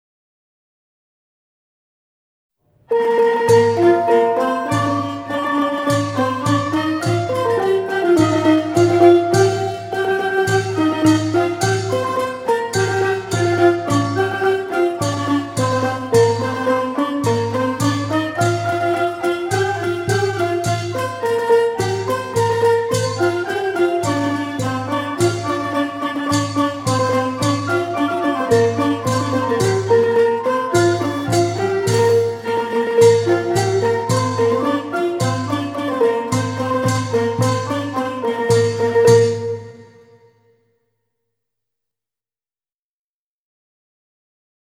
เพลงมหาฤกษ์ (วงมโหรี)